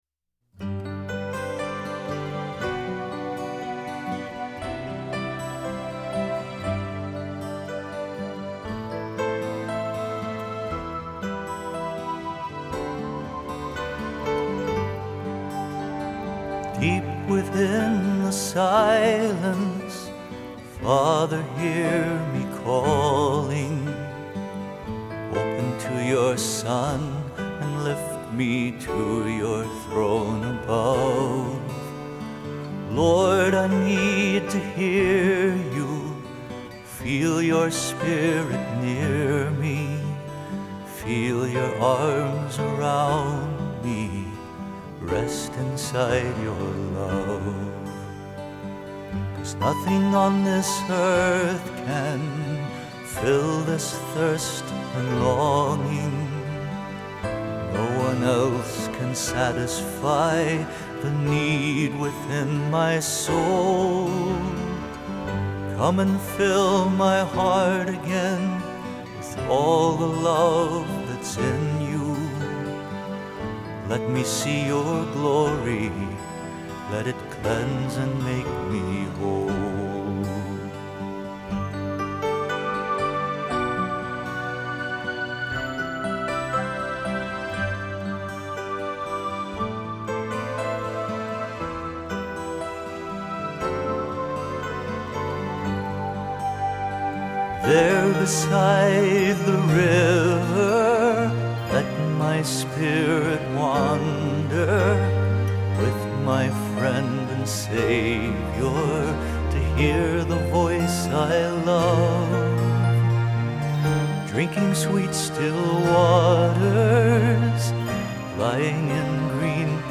song